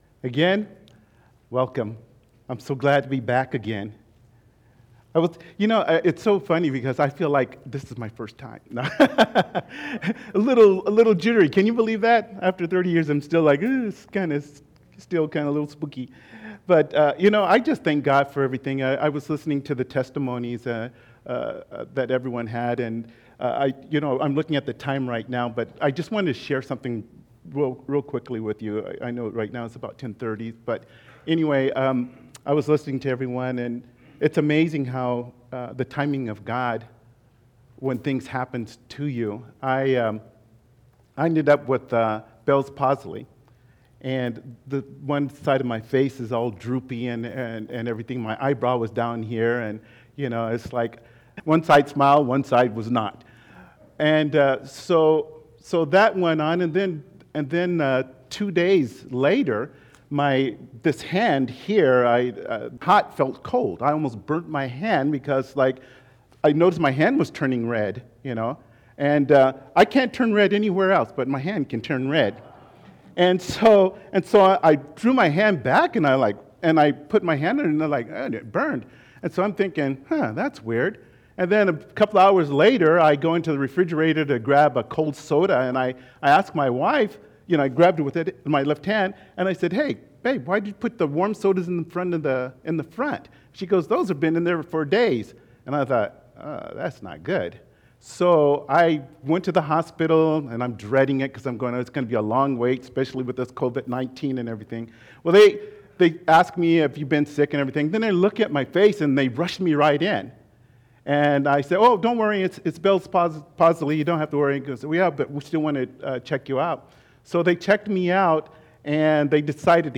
Passage: Nehemiah 8:1-12 Service Type: Sunday Morning